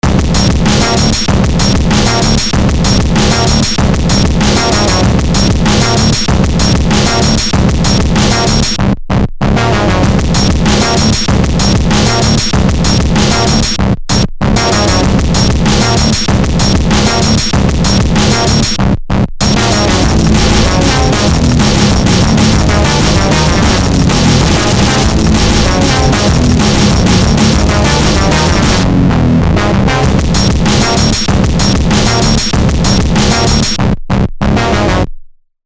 breakcore, shitcore, noisecore, glitch,